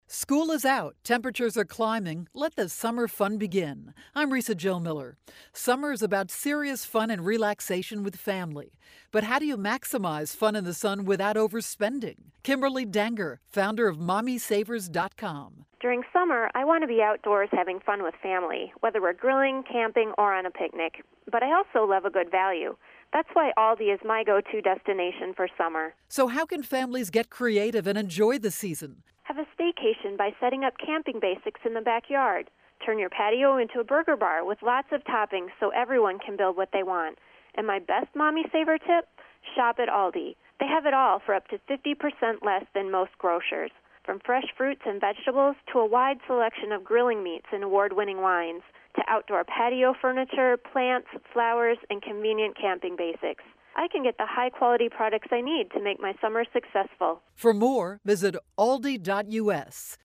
May 1, 2012Posted in: Audio News Release